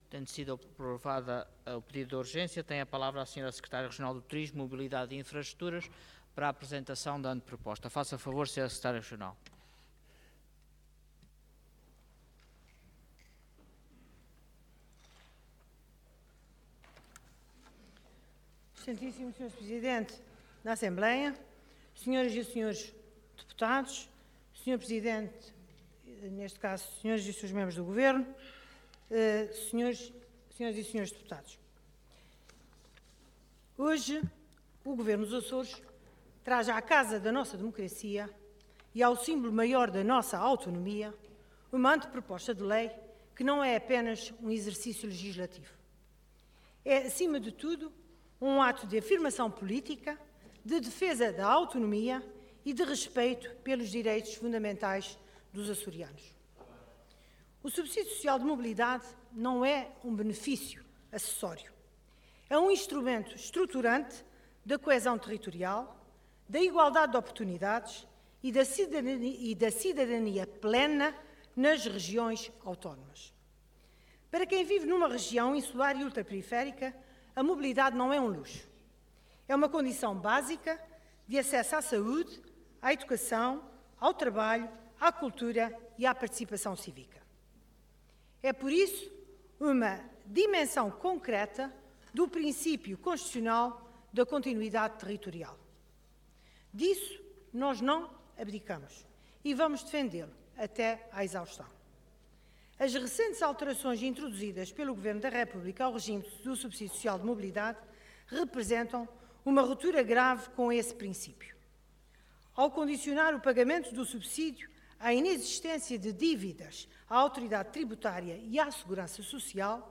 Intervenção
Orador Berta Cabral Cargo Secretária Regional do Turismo, Mobilidade e Infraestruturas